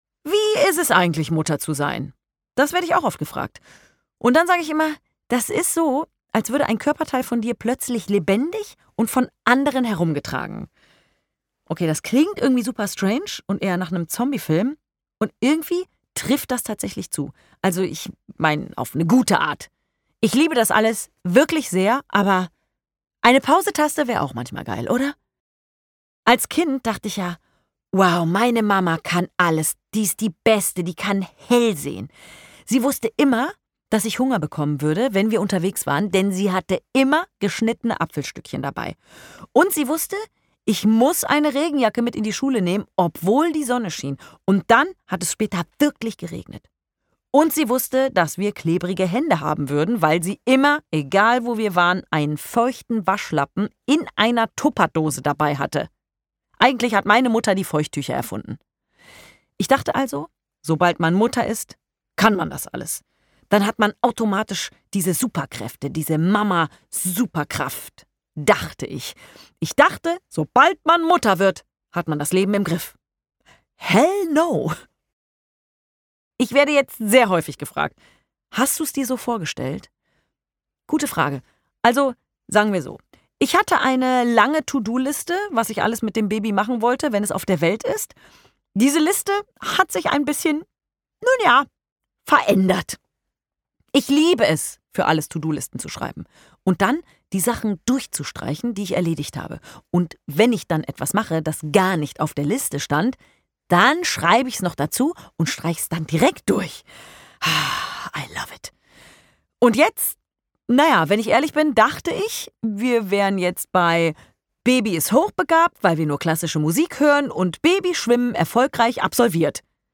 Carolin Kebekus ist auch hinter dem Hörbuch-Mikro eine mitreißende Interpretin und zieht alle Register des Komischen.